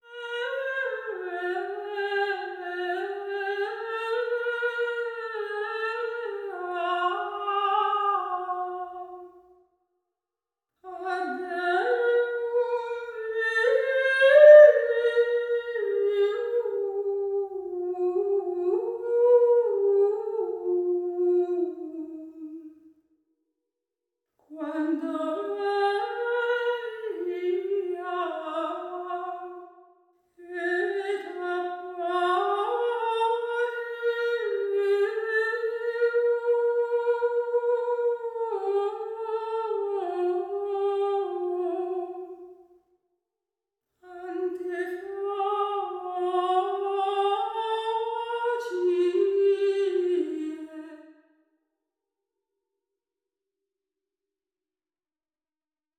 PSALM41__timeDomain_Position1.wav